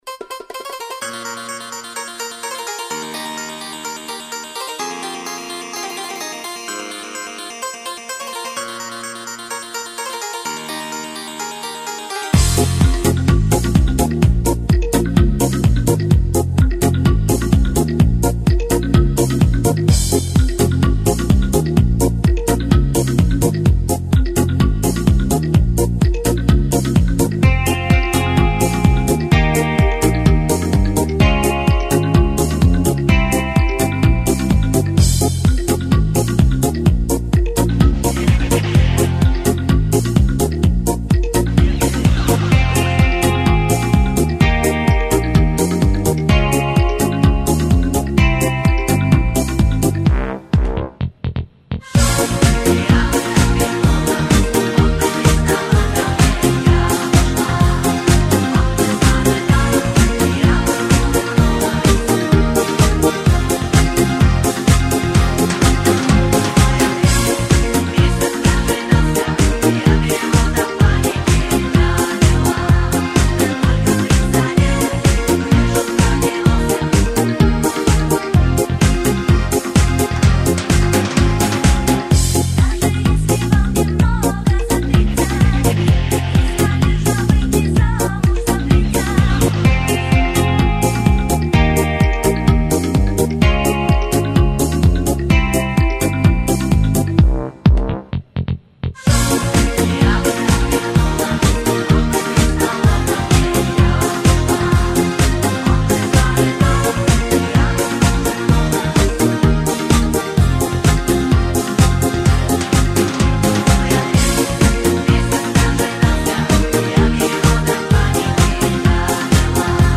Качество:Ориг+бэк